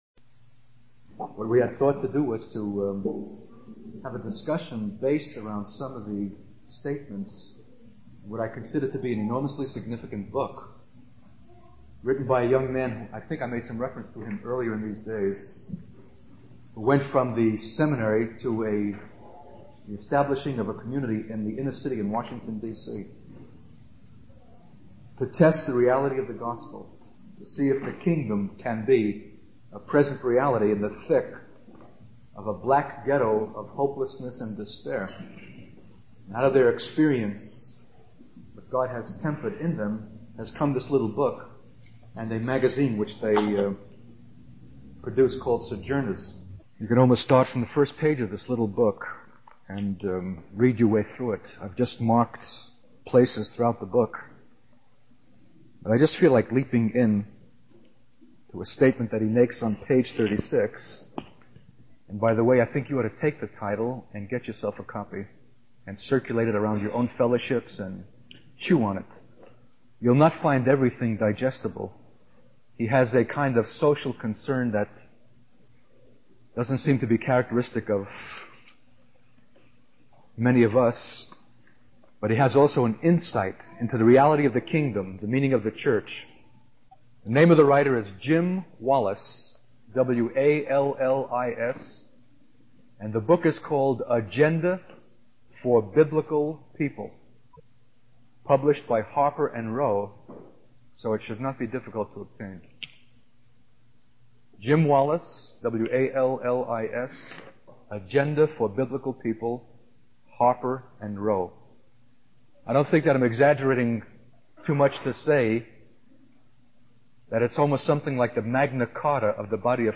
In this sermon, the speaker emphasizes the importance of Christians being representatives of another kingdom. He describes the Bible's view of change as primarily coming through the presence of God's people in history, demonstrating what human life can be in the love and power of Christ.